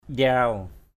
/ʄraʊ/ (d.) một loại cây họ tre.